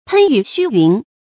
噴雨噓云 注音： ㄆㄣ ㄧㄩˇ ㄒㄩ ㄧㄨㄣˊ 讀音讀法： 意思解釋： 形容浪花水氣的飛濺散逸。